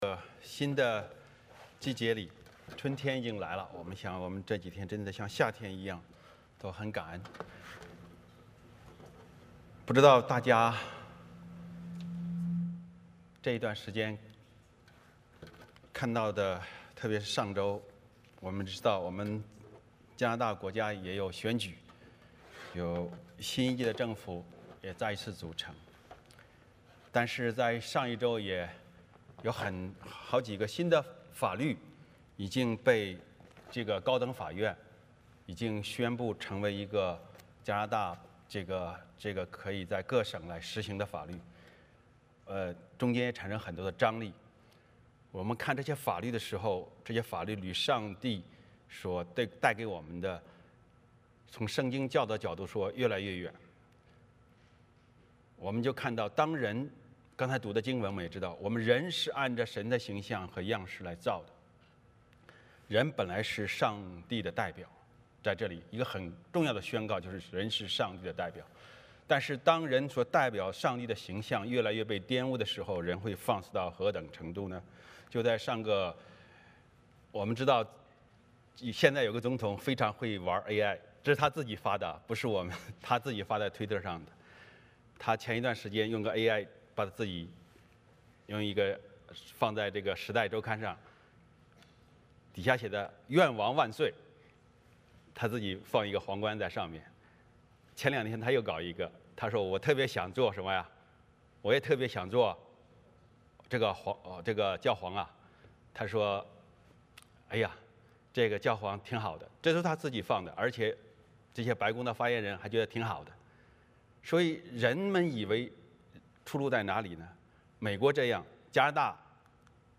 创世记 1:26-2:1 Service Type: 主日崇拜 欢迎大家加入我们的敬拜。